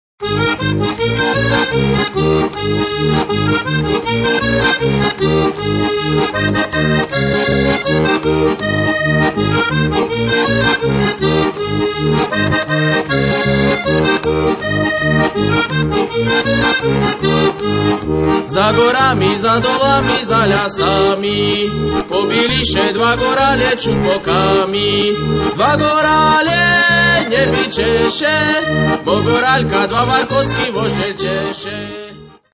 Kategória: Ľudová hudba